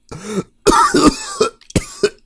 Cough3.ogg